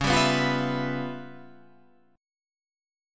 C#7#9b5 chord